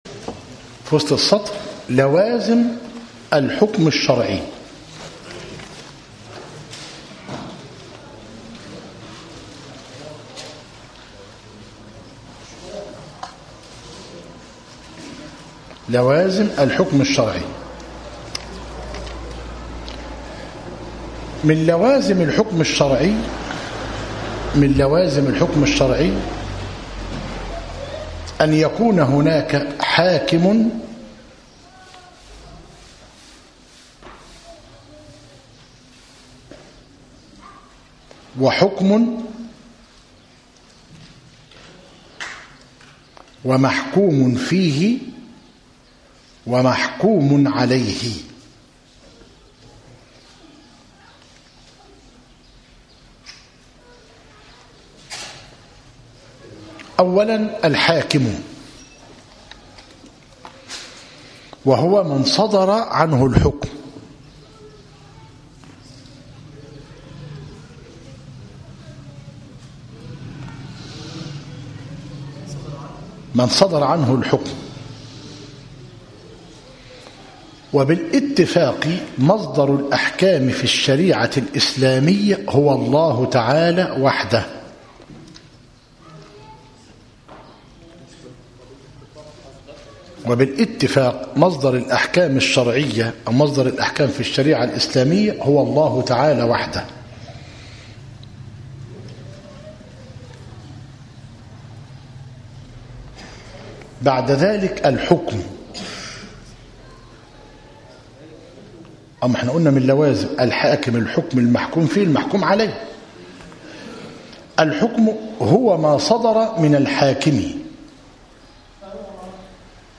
من مادة مذكرة أصول الفقه للعلامة الشنقيطي رحمه الله - مسجد التوحيد - ميت الرخا - زفتى - غربية - المحاضرة الخامسة - بتاريخ 15- جماد آخر- 1436هـ الموافق 4 - إبريل - 2015 م .